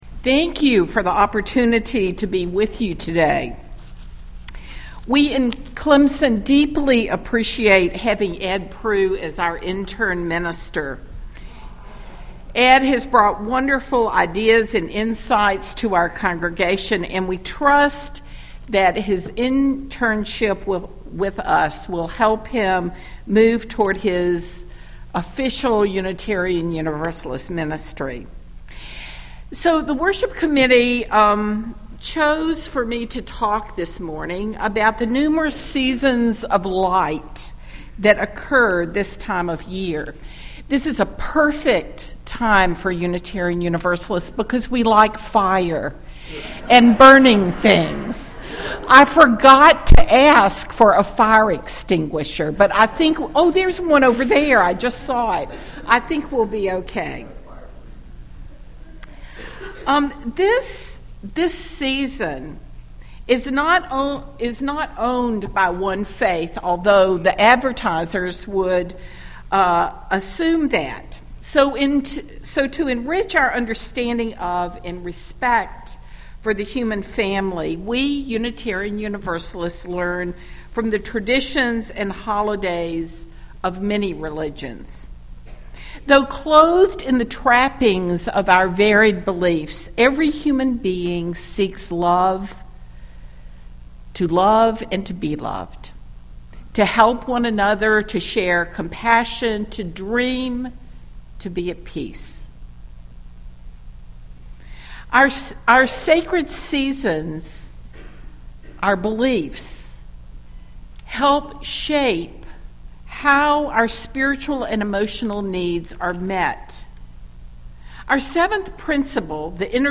Celebrating the Seasons of Light (and Ingathering) - Greenville Unitarian Universalist Fellowship